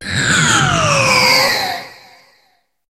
Cri de Lugulabre dans Pokémon HOME.